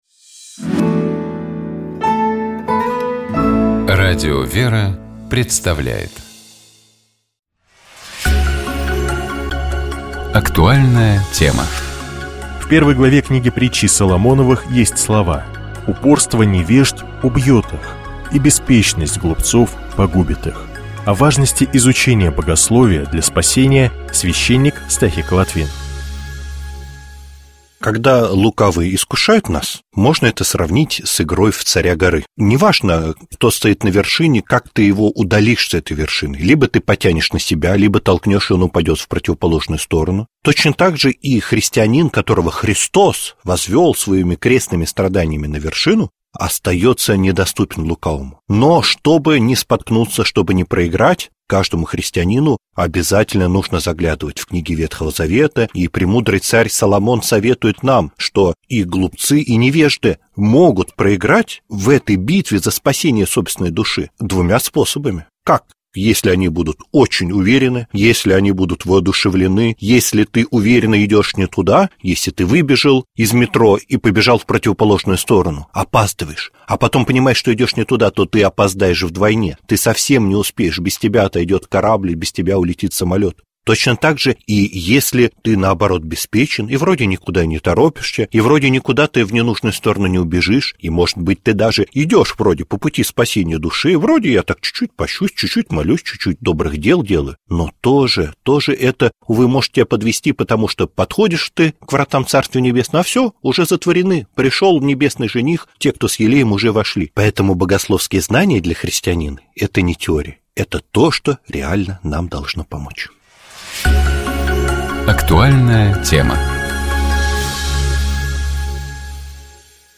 Псалом 97. Богослужебные чтения - Радио ВЕРА